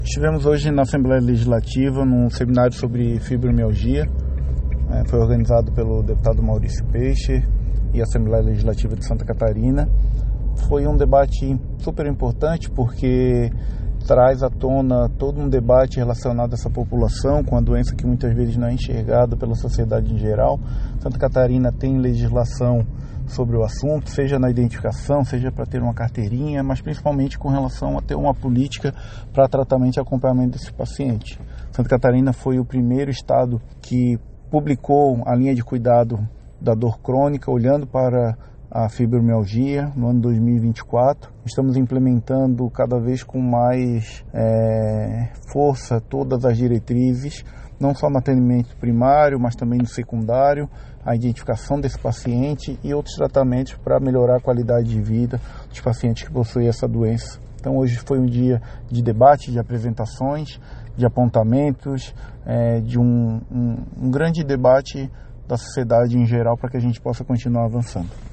O secretário de Estado da Saúde, Diogo Demarchi Silva, ressaltou o pioneirismo do estado nna elaboração de políticas de tratamento e acompanhamento de doenças de dor crônica: